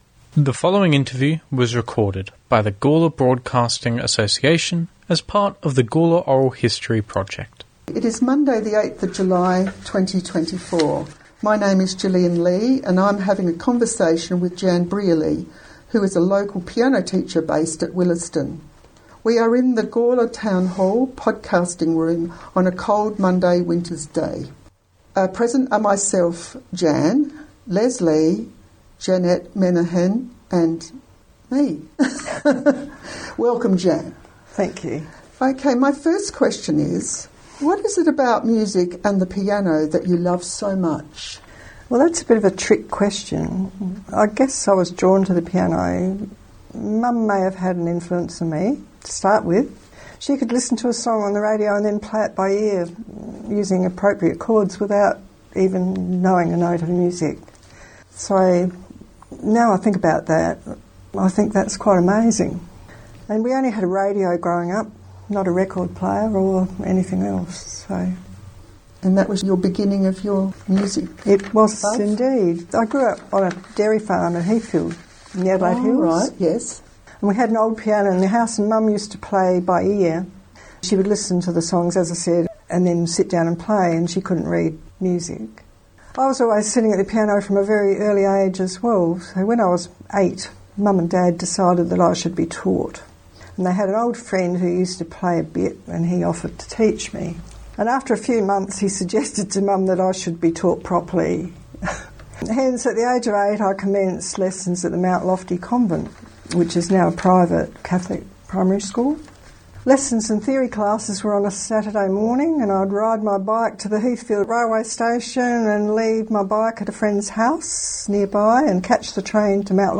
This interview recorded by the Gawler Broadcasting Association, Oral History Project, is available for download.